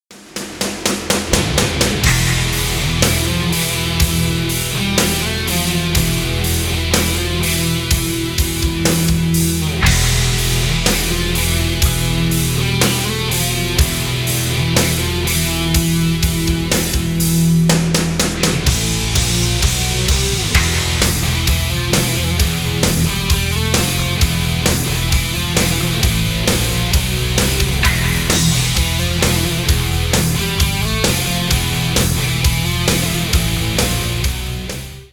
интро , металл , рок